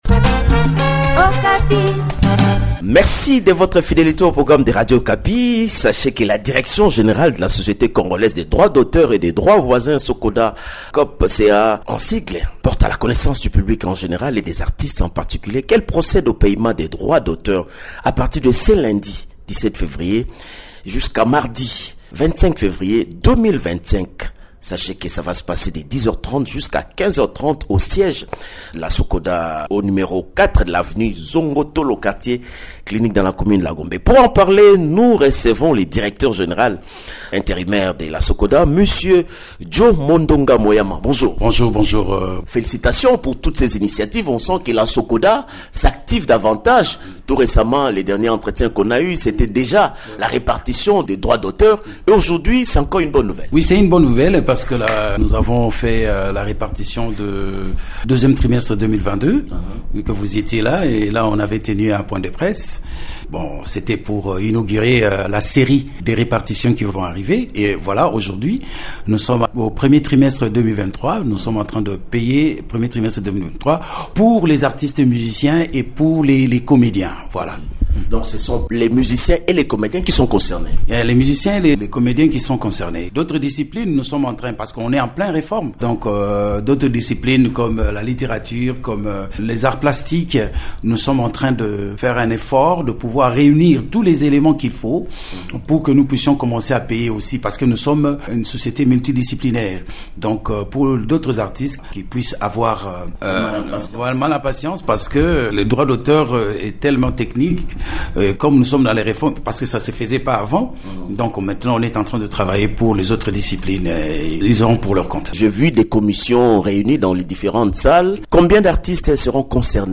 Dans un entretien accordé à Radio Okapi